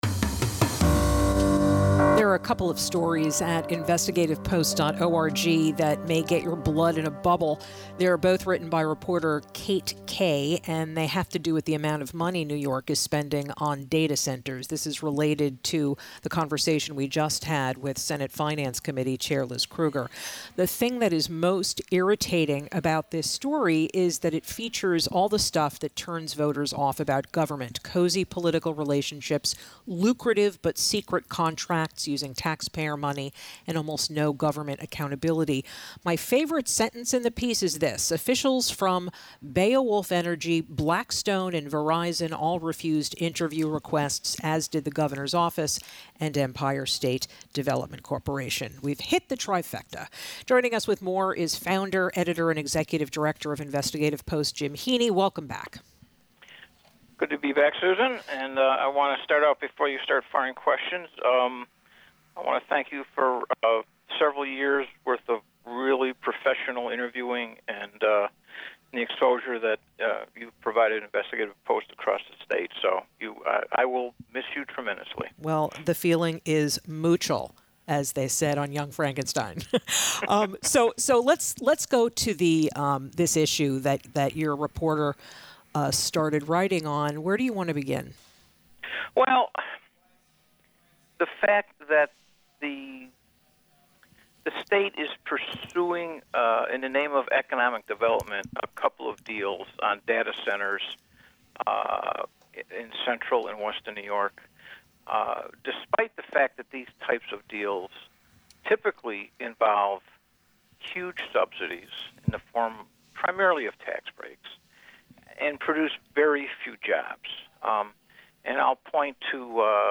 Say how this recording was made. The interview aired on The Capitol Pressroom.